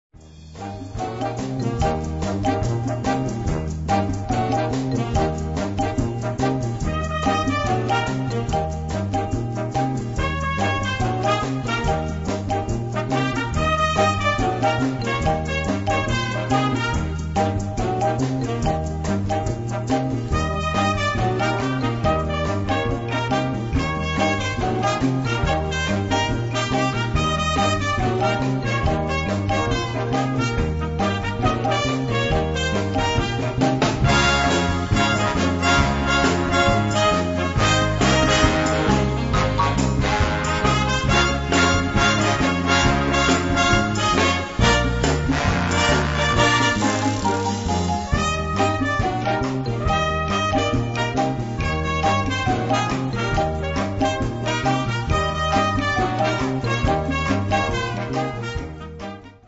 Gattung: Latin Rock-Solo für 2 Trompeten
Besetzung: Blasorchester